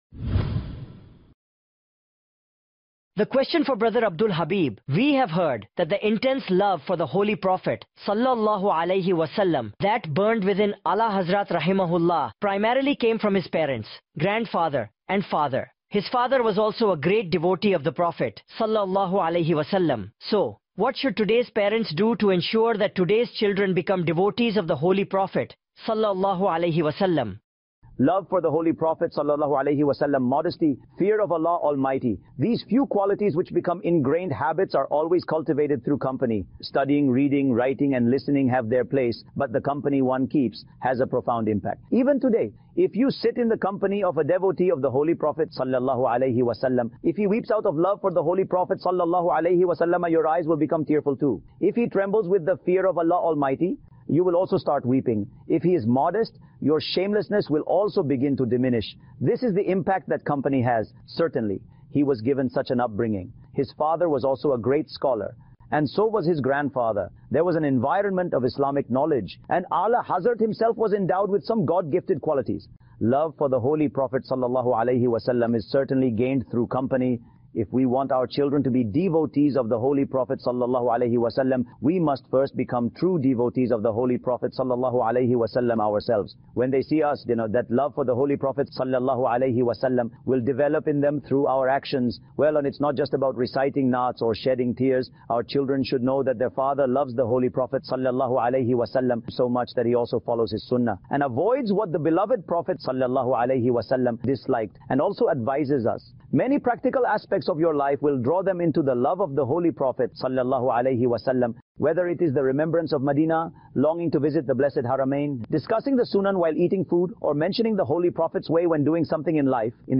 Parents' Act To Make Their Kids Devotees of Rasool صلی اللہ علیہ واٰلہ وسلم (AI-Generated) Sep 26, 2024 MP3 MP4 MP3 Share Parents' Act To Make Their Kids Devotees of Rasool صلی اللہ علیہ واٰلہ وسلم (AI-Generated)